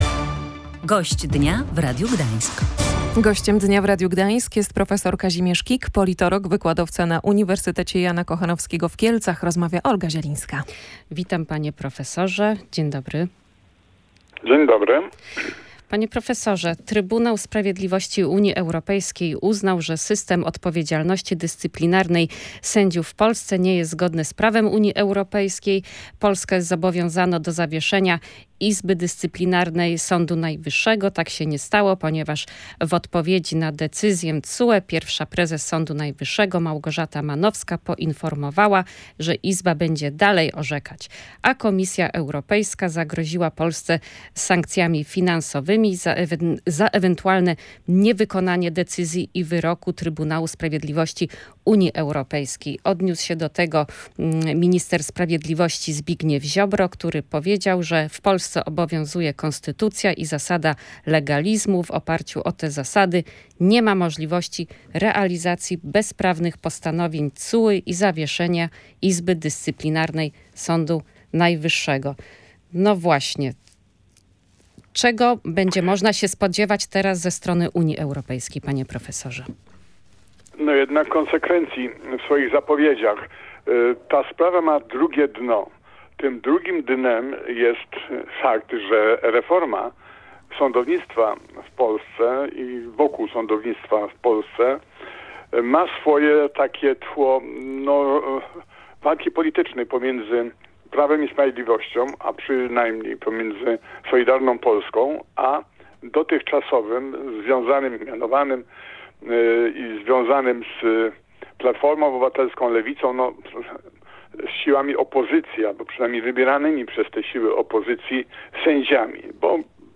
W audycji „Gość Dnia Radia Gdańsk” rozmawialiśmy także o zachowaniu Donalda Tuska na wiecu w Gdańsku i planowanych działaniach prowokacyjnych ze strony lidera PO wobec PiS.